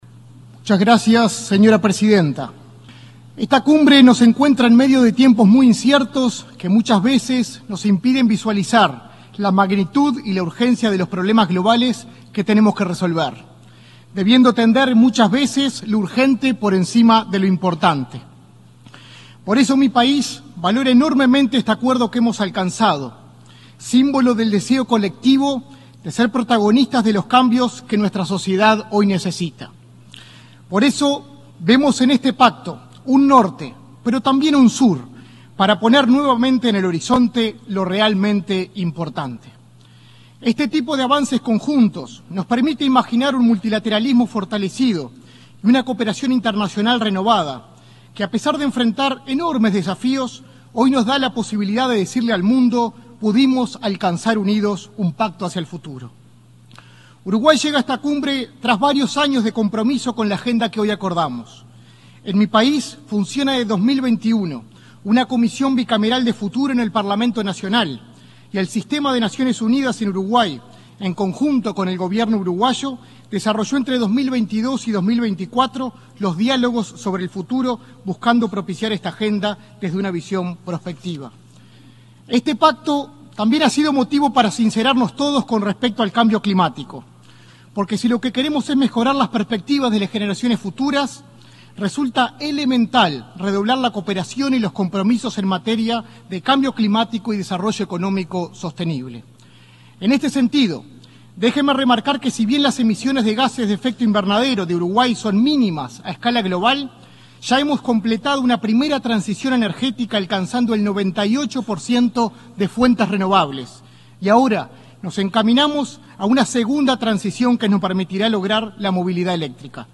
Palabras del subsecretario de Relaciones Exteriores, Nicolás Albertoni
Palabras del subsecretario de Relaciones Exteriores, Nicolás Albertoni 23/09/2024 Compartir Facebook X Copiar enlace WhatsApp LinkedIn En el marco de la Cumbre del Futuro de la Organización de Naciones Unidas (ONU), este 23 de setiembre, se expresó el subsecretario del Ministerio de Relaciones Exteriores, Nicolás Albertoni.